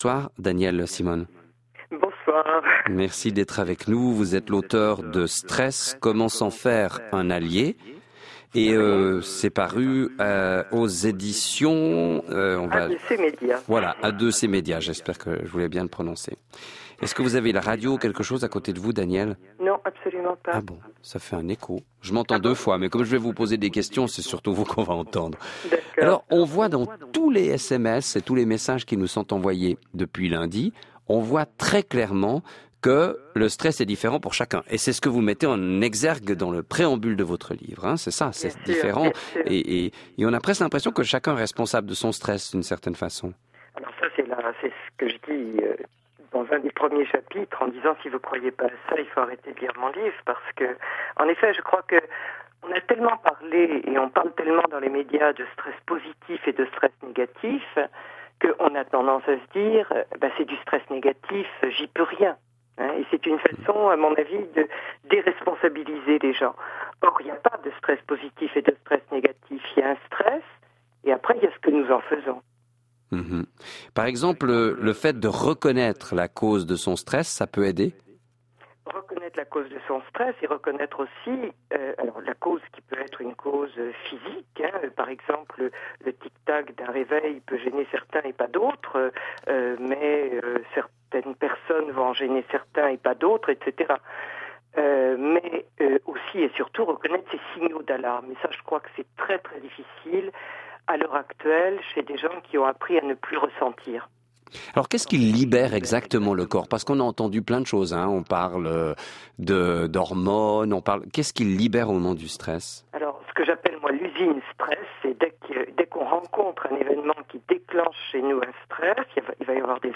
Interview Radio Protestante